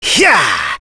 Fluss-Vox_Attack5.wav